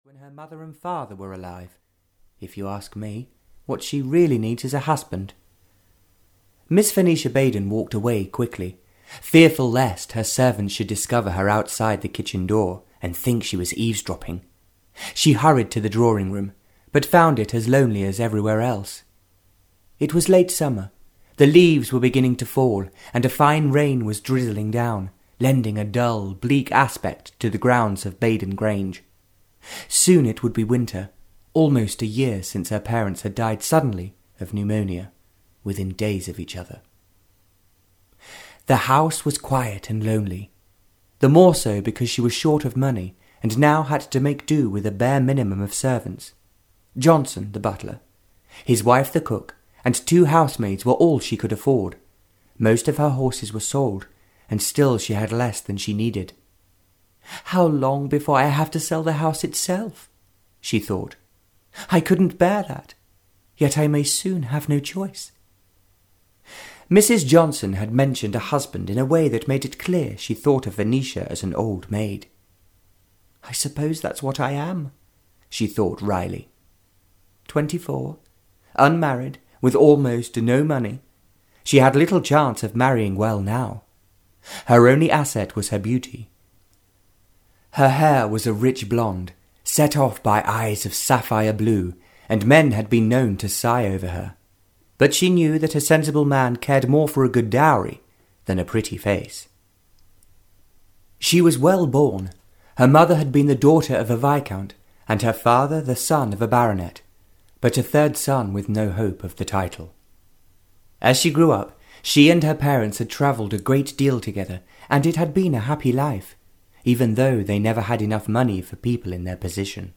Sailing to Love (EN) audiokniha
Ukázka z knihy